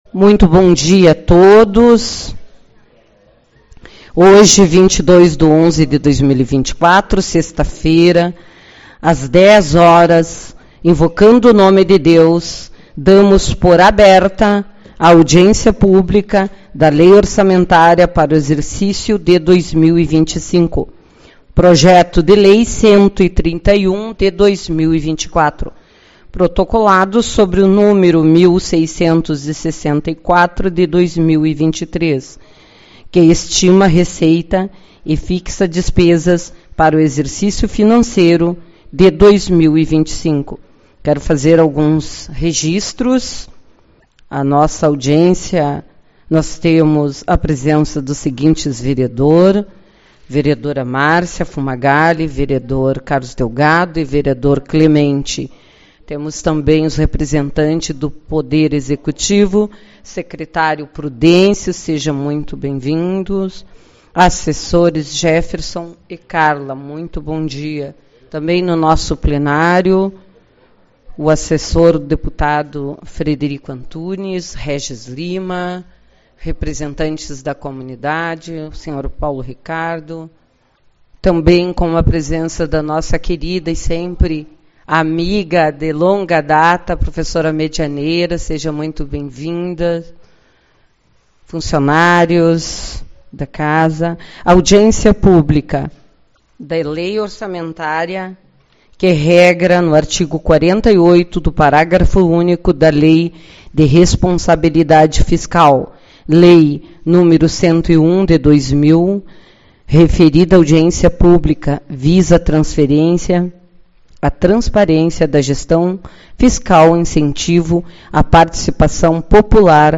22/11 - Audiência Pública - LOA